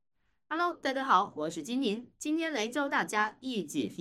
底部模型推理
在未给底部模型加入原音频数据情况下，底部模型的推理效果也能达到6-7分相似。